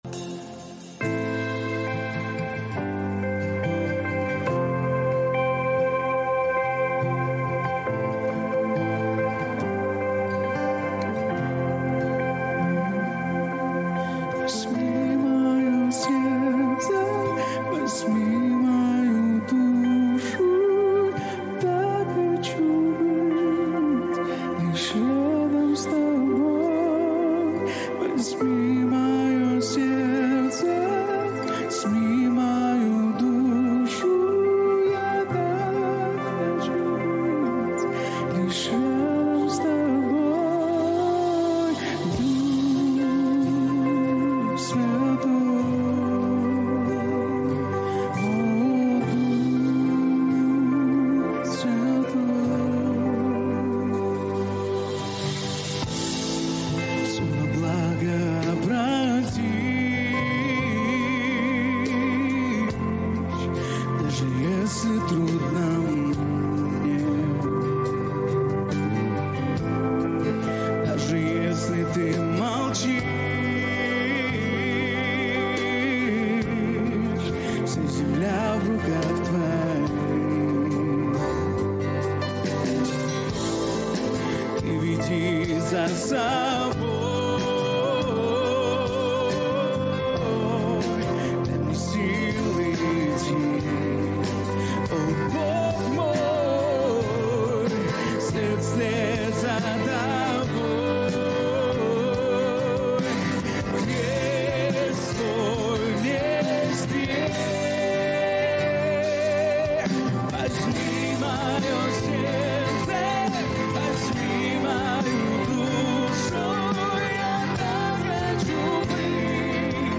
465 просмотров 47 прослушиваний 3 скачивания BPM: 70